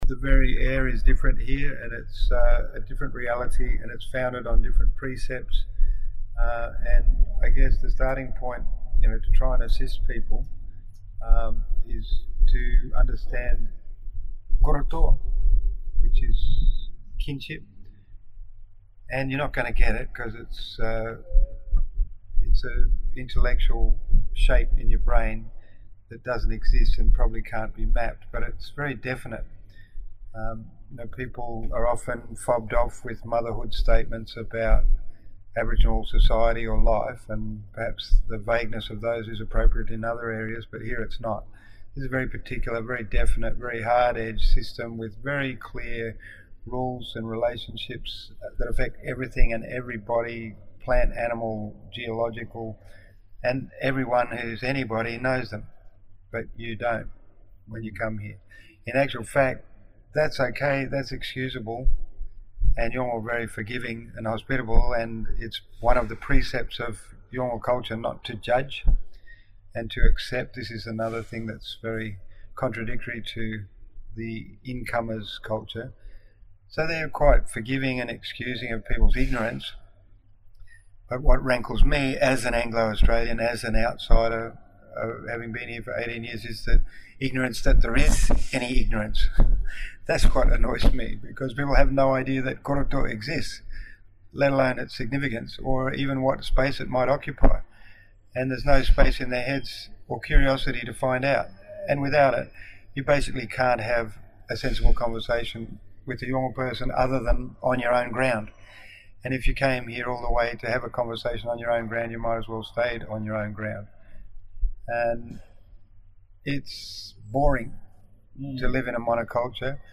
Apologies for the quality of the recording